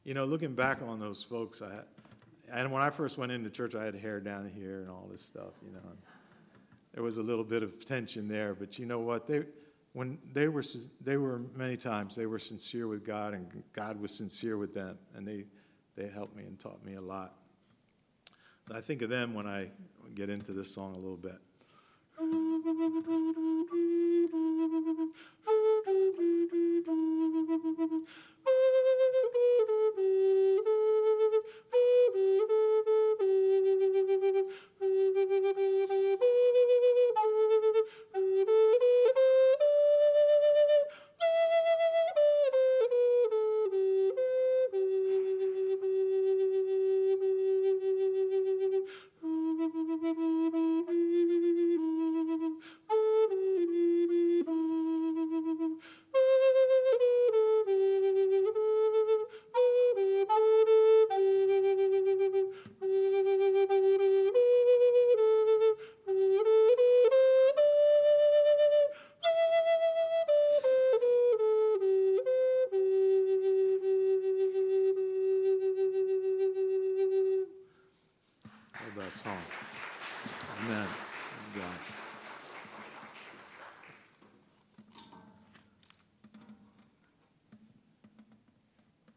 Voice, Guitar and Recorder- Laurel Hill Bible Church Seniors Luncheon in Clementon,NJ October 10, 2017